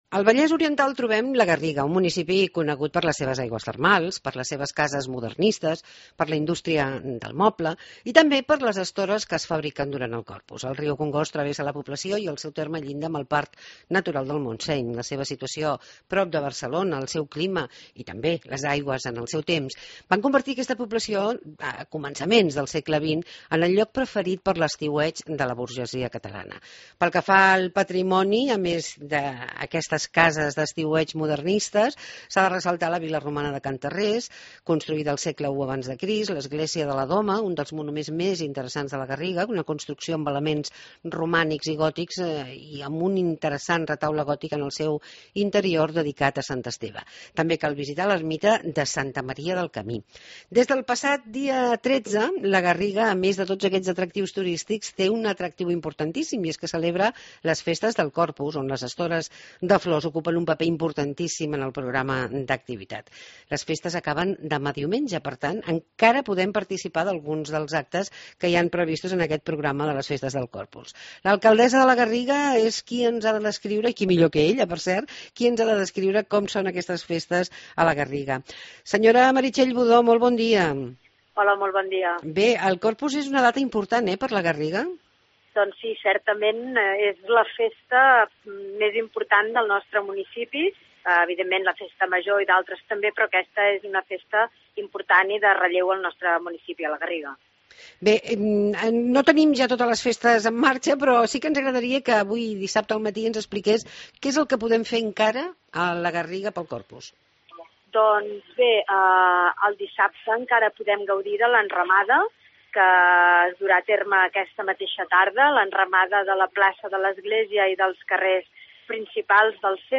Proposta ACT: Les festes del Corpus a la Garriga . Ens ho explica Meritxell Budó, alcaldessa del municipi.